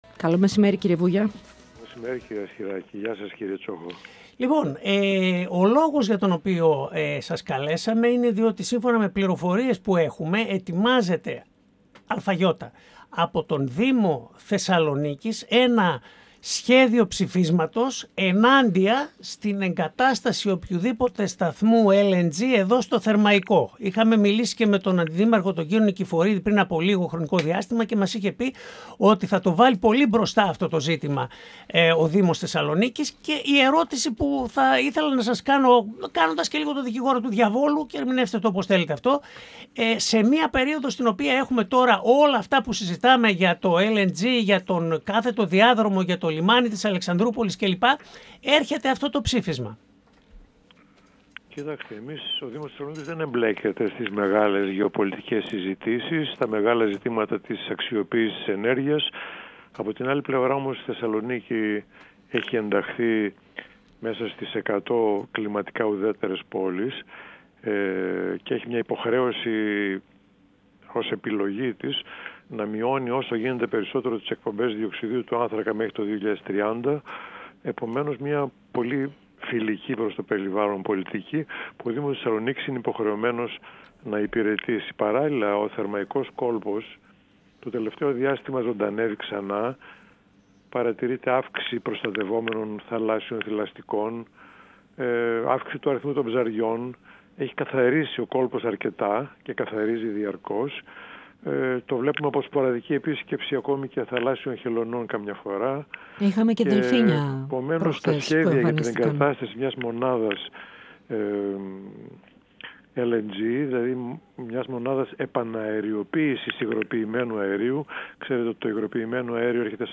Ο Σπύρος Βούγιας, Πρόεδρος Δημοτικού Συμβουλίου Θεσσαλονίκης, στον 102FM | «Επόμενη Στάση: Ενημέρωση» | 26.11.2025
Συνεντεύξεις